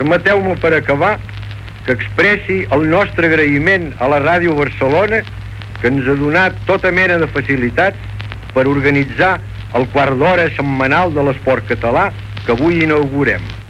Agraïment en la primera edició del programa.
Esportiu